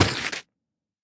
biter-roar-mid-6.ogg